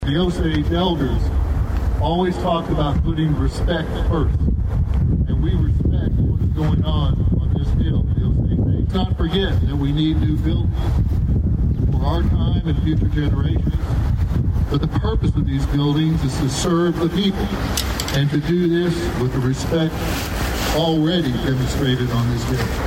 Osage Nation Principal Chief Geoffrey Standing Bear was also in attendance on Monday.